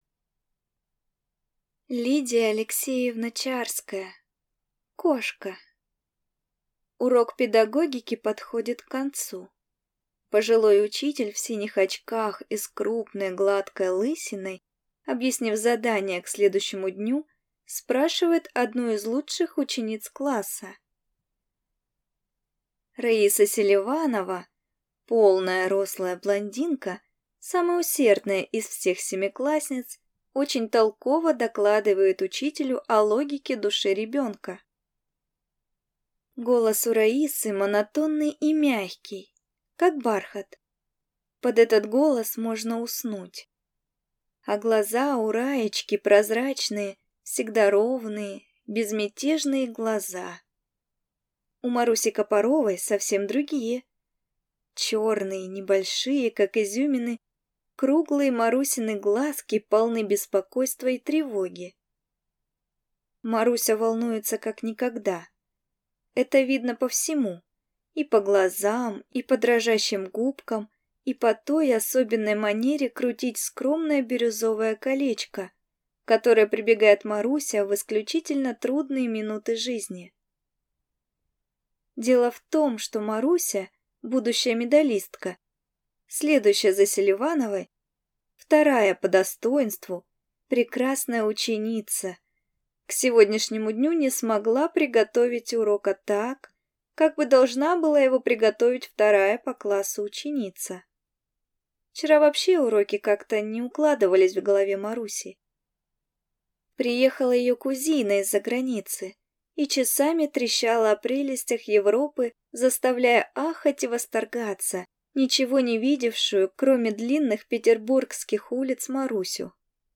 Аудиокнига Кошка | Библиотека аудиокниг